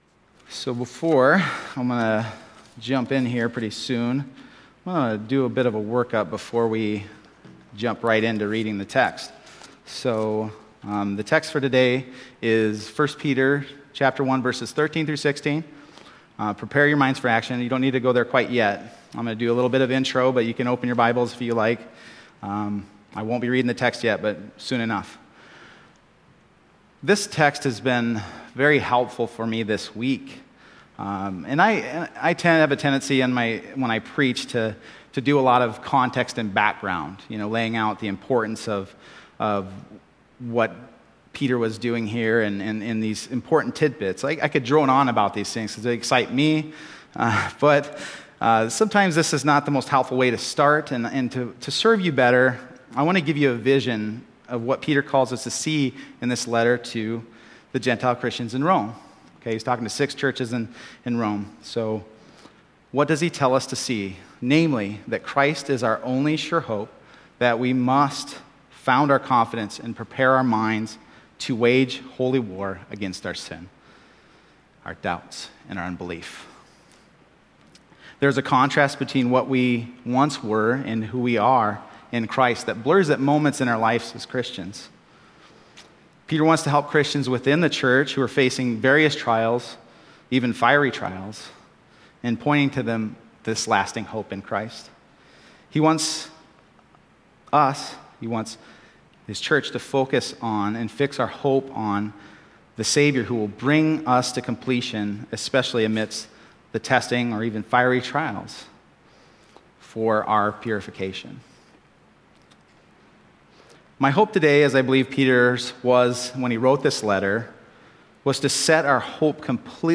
Other Passage: 1 Peter 1:13-16 Service Type: Sunday Morning 1 Peter 1:13-16 « Confidence at the Judgment You Must Be Born Again